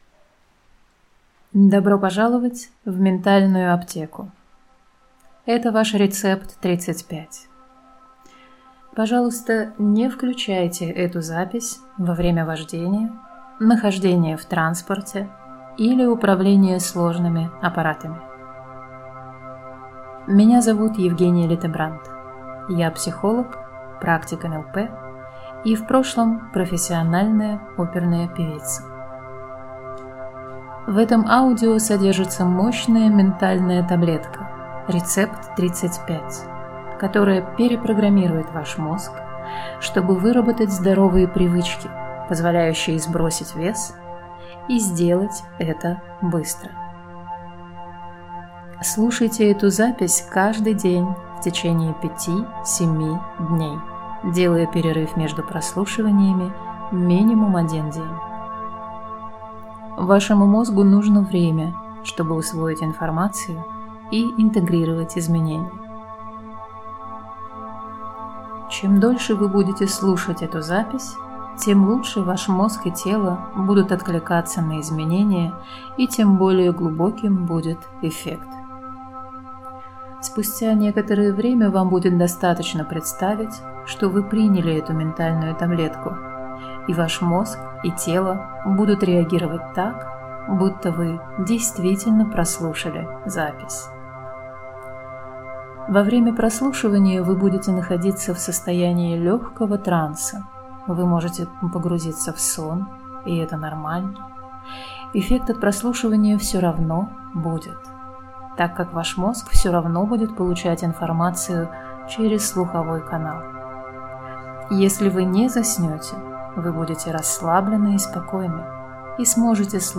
РЕЦЕПТ-35-Бинауралi9r4j5nrfud.mp3